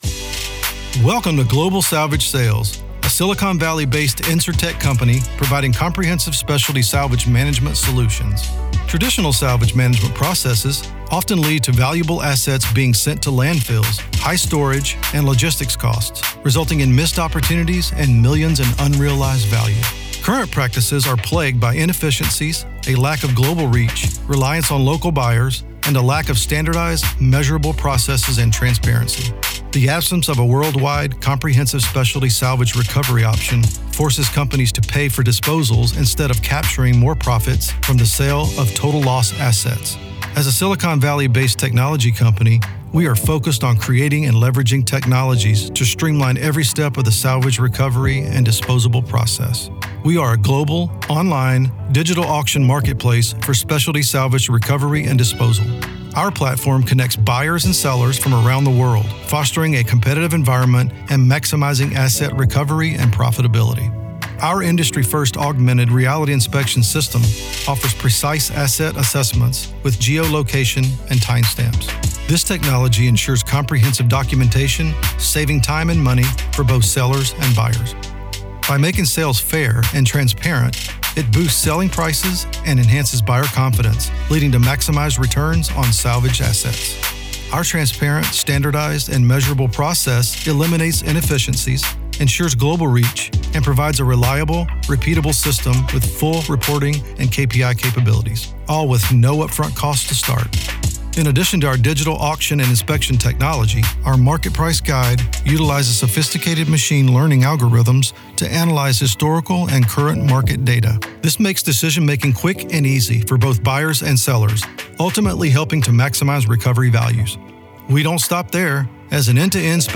Online Corporate Business Advert